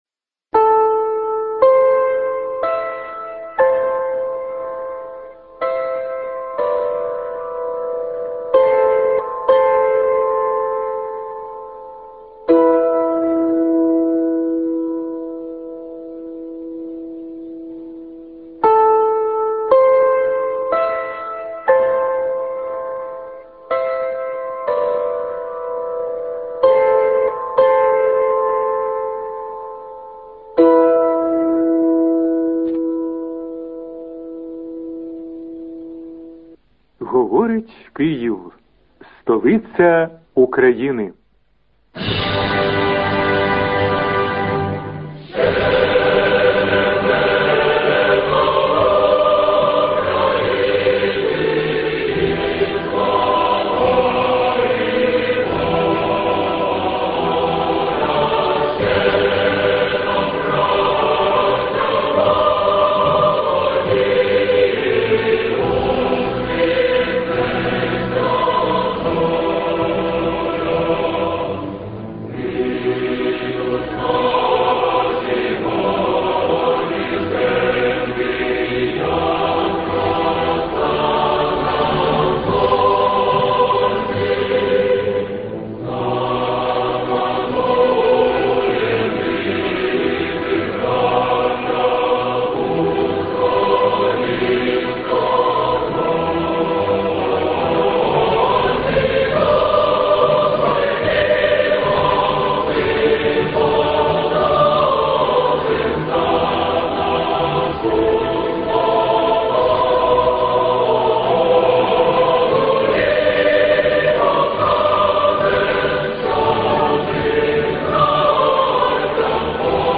позивні 2001 р.